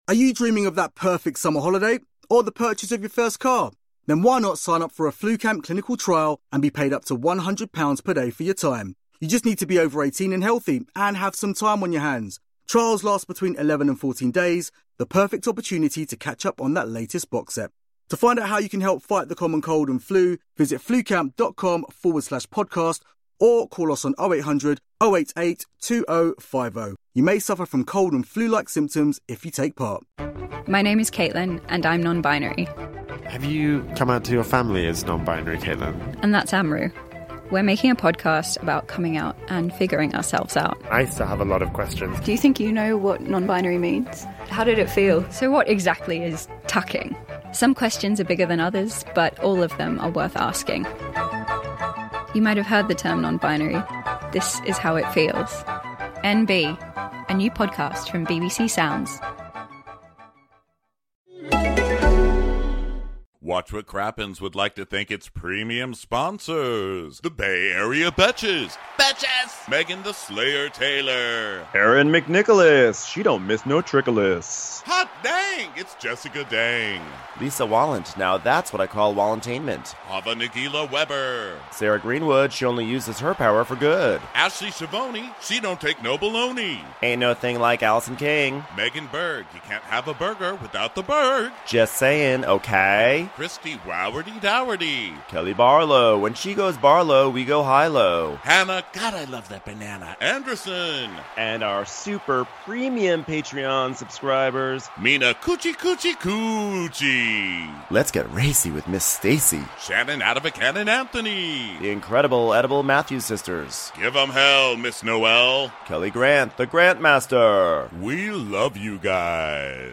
Live in Cinci for this very special episode of Real Housewives of New York. Will Luann get kicked off her high horse when she tries to keep her enemies away from a clam bake or will Tyler Perry save the day with the deepest quote in Housewives history?